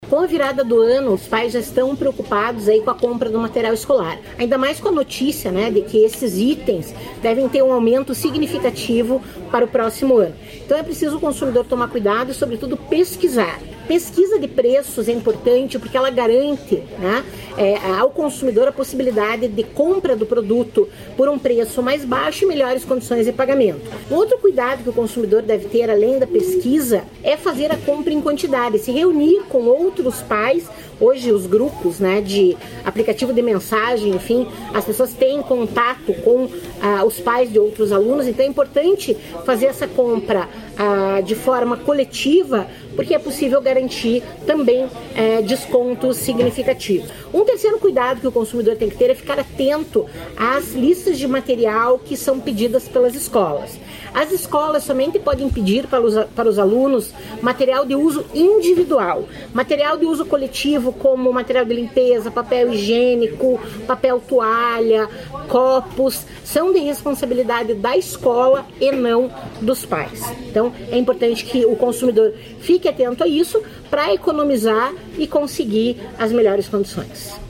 Sonora da diretora do Procon, Cláudia Silvano, sobre a compra de material escolar